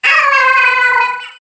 One of Yoshi's voice clips in Mario Kart Wii